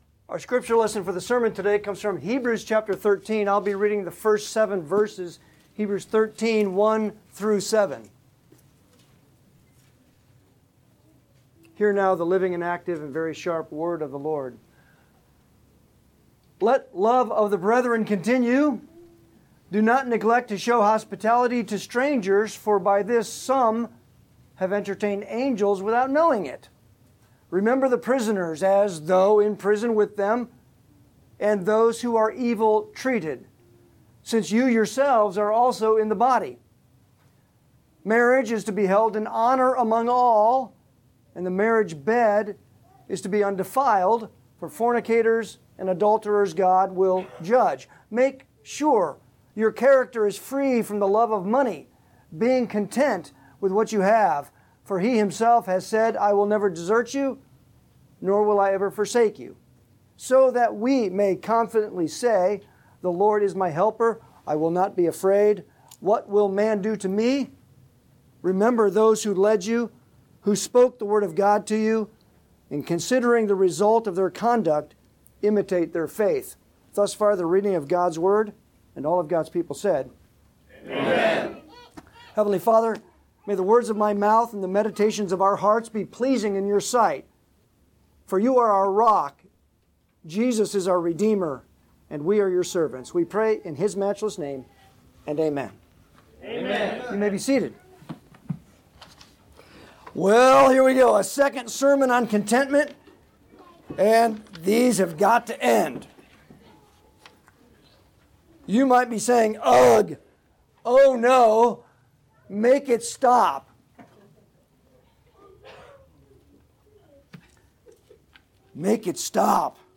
Passage: Hebrews 13:1-7 Service Type: Sunday Sermon